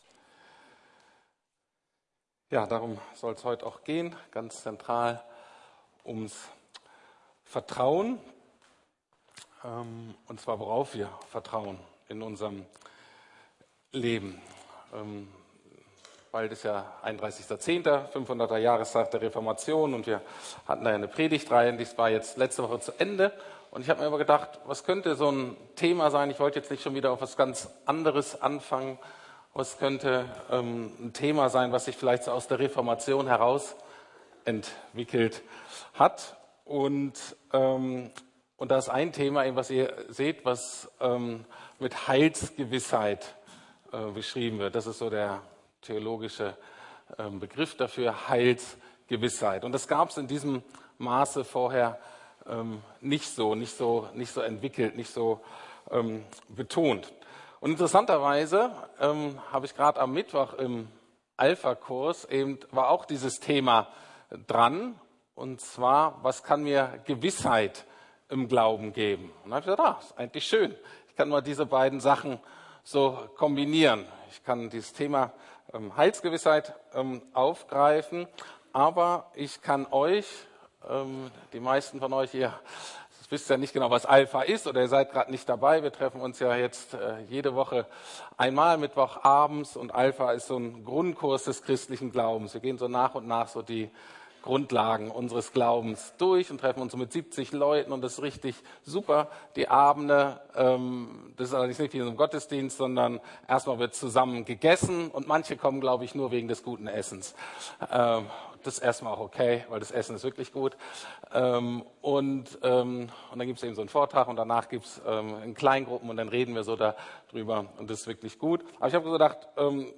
Heilsgewissheit: Kann bzw. darf ich wissen, dass ich ewiges Leben habe? ~ Predigten der LUKAS GEMEINDE Podcast